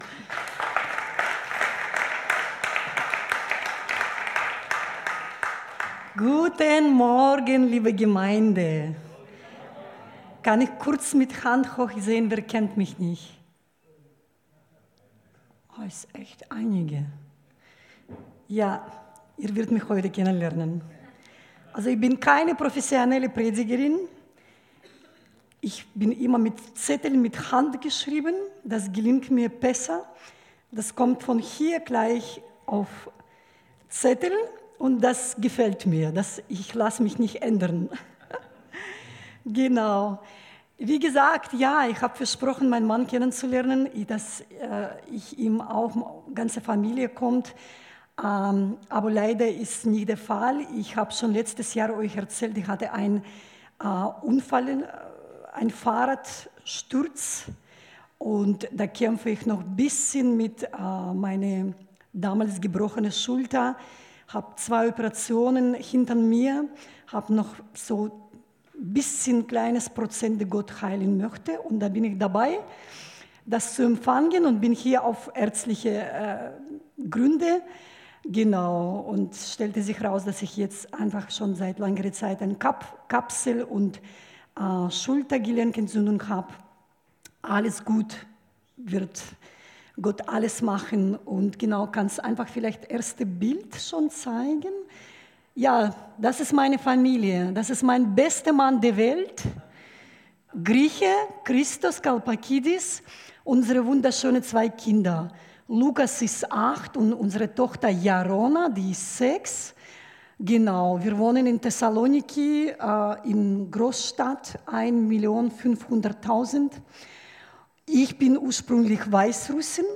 Bericht über die Missionsarbeit in Griechenland